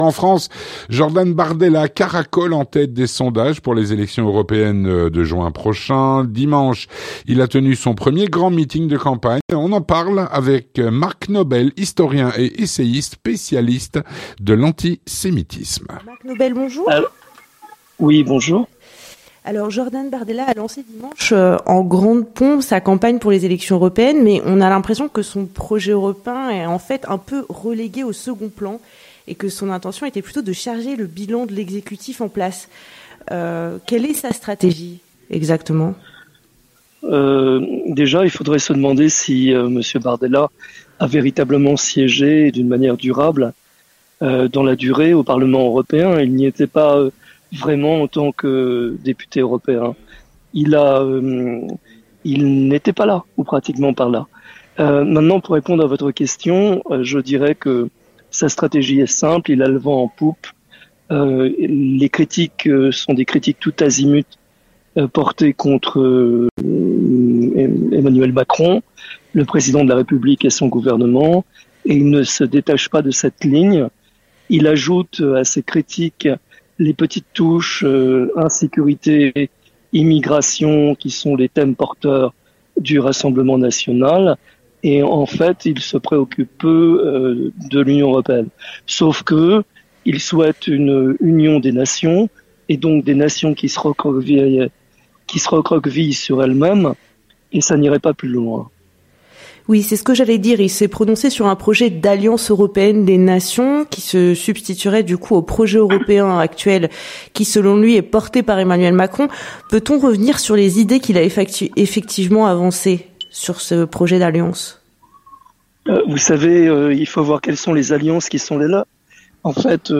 L'entretien du 18H - Jordan Bardella et le RN caracolent en tête des sondages pour les élections européennes.